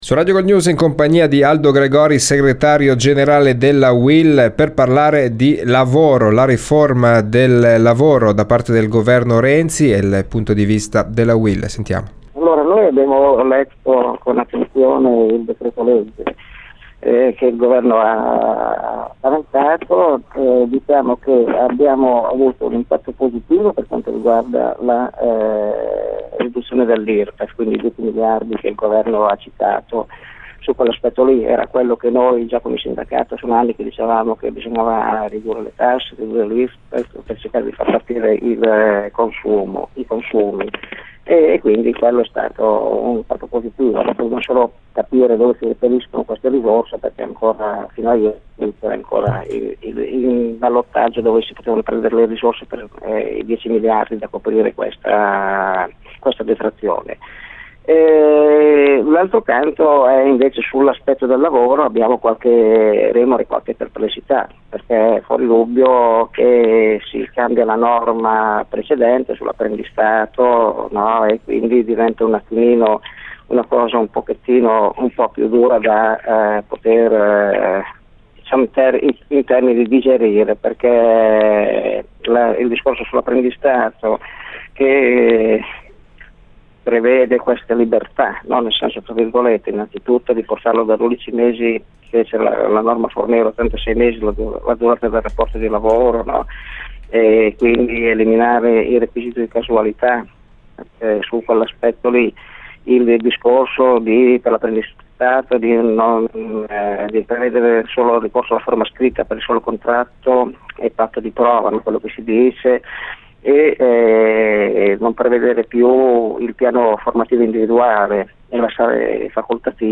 intervista rilasciata a Radio Gold